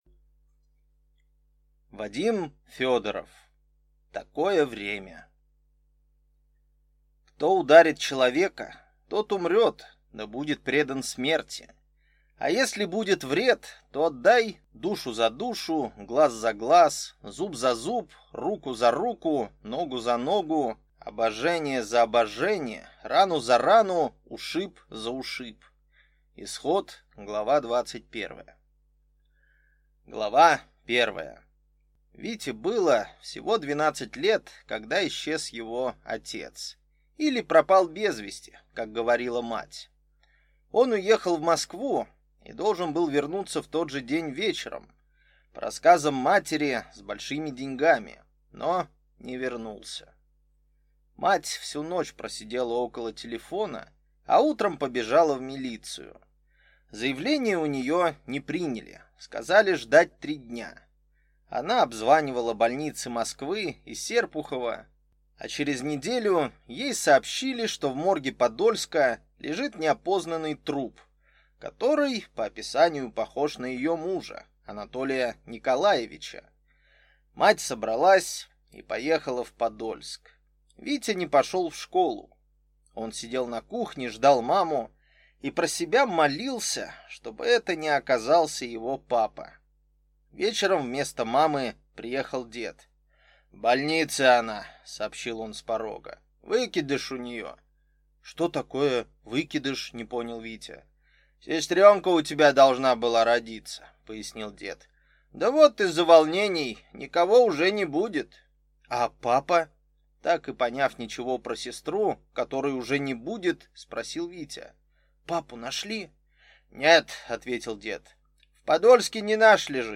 Аудиокнига Такое время | Библиотека аудиокниг
Прослушать и бесплатно скачать фрагмент аудиокниги